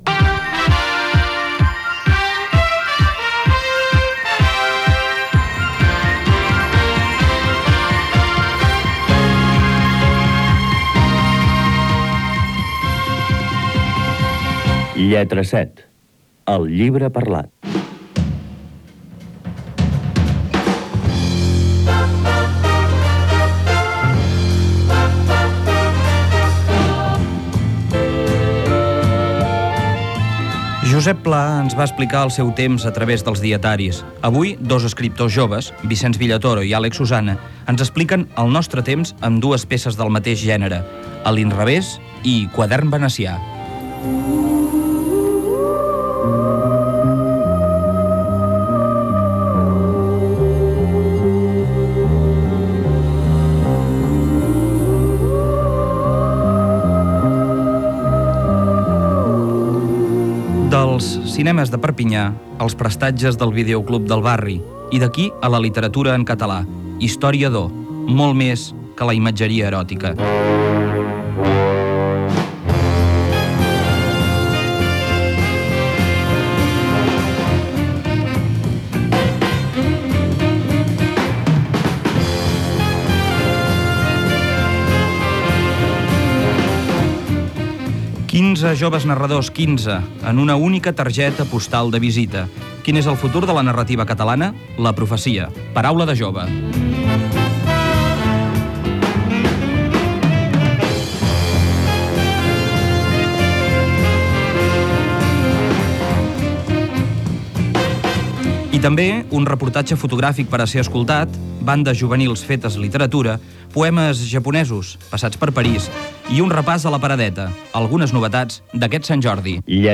Careta del programa, sumari, indicatiu, comentari sobre el llibre "A l'inrevés" de Vicenç Villatoro i lectura d'un fragment
FM